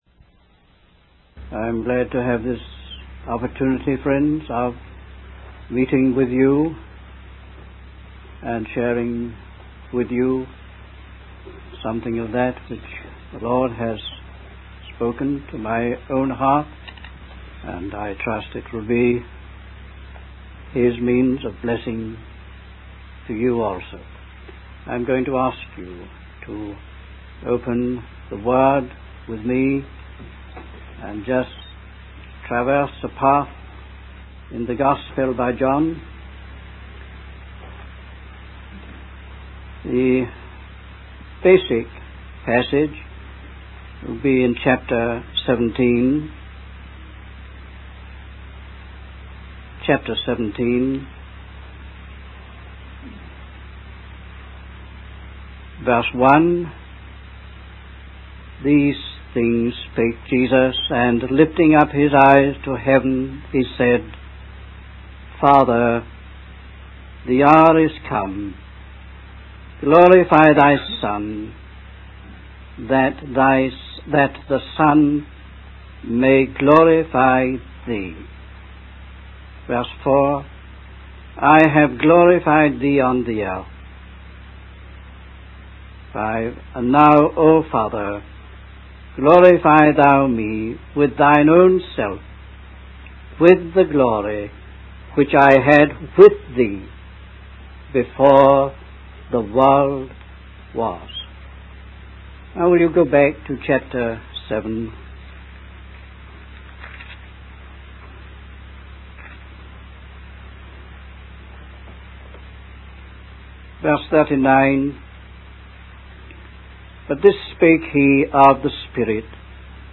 In this sermon, the speaker emphasizes the theme of hopelessness and impossibility in various situations. He refers to the story of Jesus feeding the five thousand, where the disciples believed it was impossible to provide enough bread for such a large crowd.